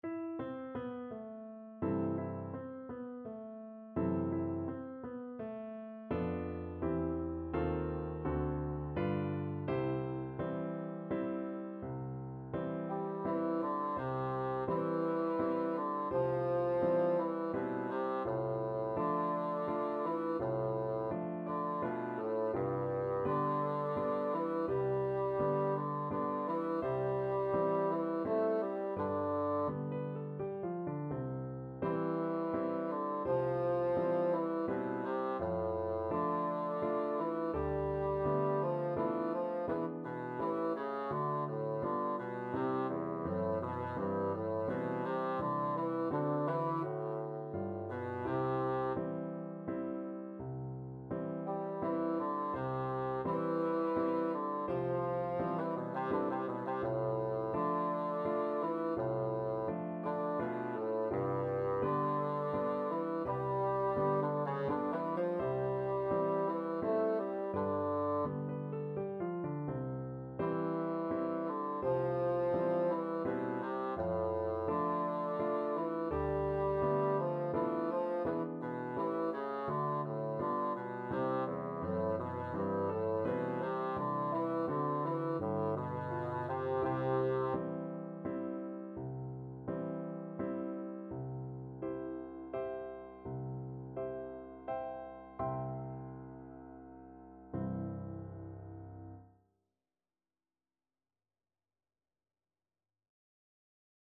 Bassoon version
= 84 Andante non troppe e molto maestoso
3/4 (View more 3/4 Music)
Classical (View more Classical Bassoon Music)